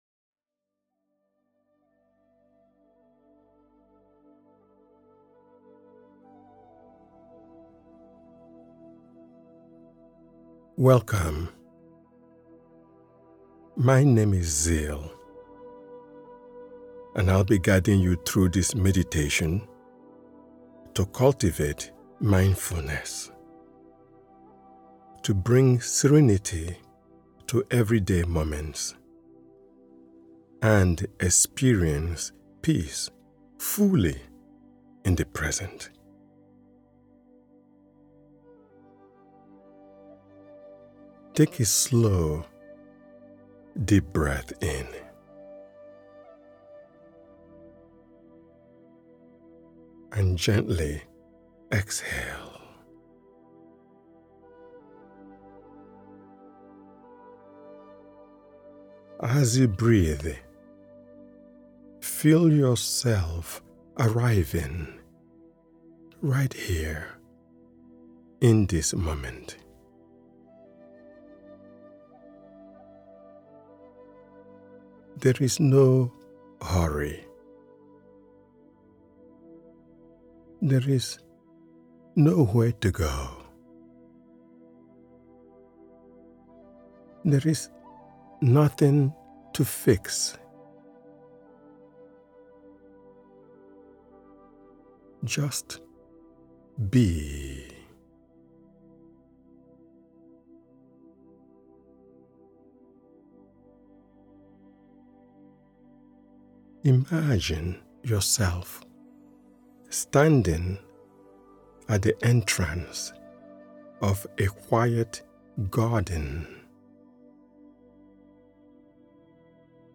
The Miracle of Mindfulness is a gentle guided meditation that invites you to slow down, quiet the mind, and return fully to the present moment.
Ideal for morning reflection, stress relief, or evening centering, this meditation offers a soft return to presence—one mindful breath at a time.